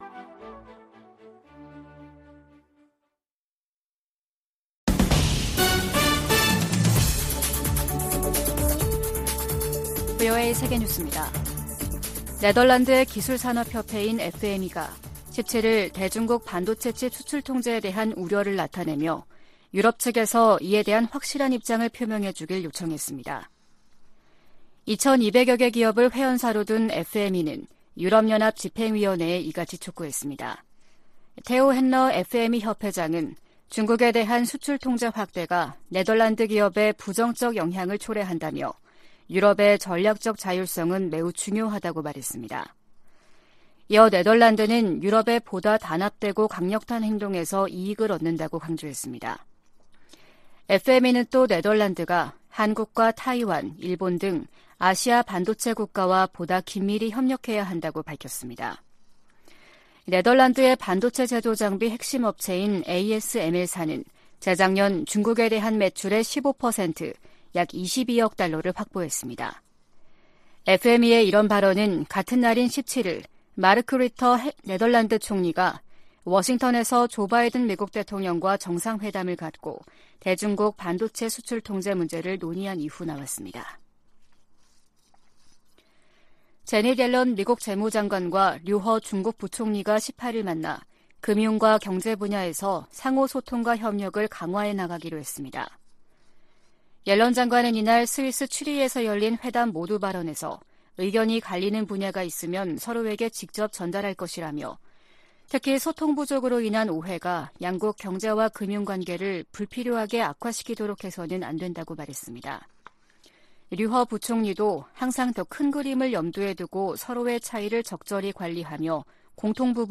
VOA 한국어 아침 뉴스 프로그램 '워싱턴 뉴스 광장' 2023년 1월 19일 방송입니다. 미 국방부는 로이드 오스틴 국방장관이 곧 한국을 방문할 것이라고 밝혔습니다. 한국 통일부가 올해 북한과의 대화 물꼬를 트겠다는 의지를 밝히고 있으나 북한이 호응할 가능성은 크지 않다는 관측이 나오고 있습니다.